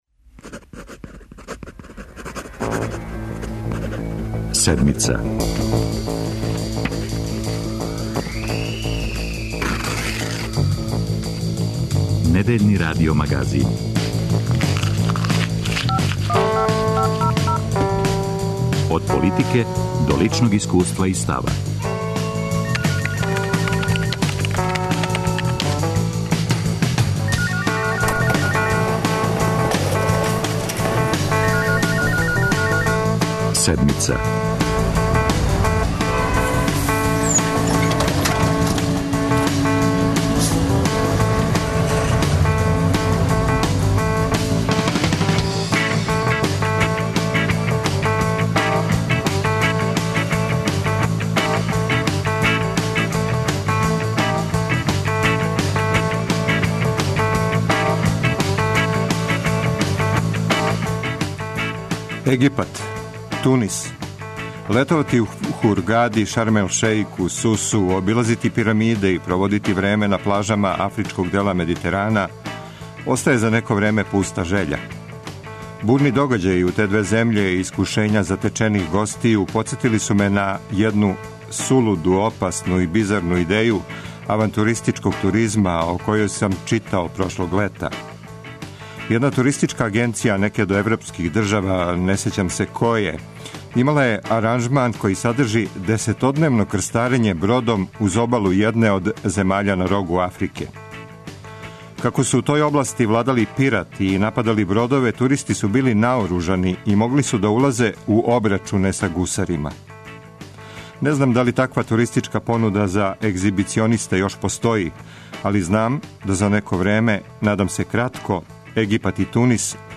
Тема трибине Седмице посвећена је предстојећем митингу српске опозиције у Београду.